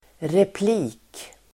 Ladda ner uttalet
Uttal: [repl'i:k]